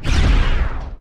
Wula_Disturber_Turret_Weapon_Shootingsound.wav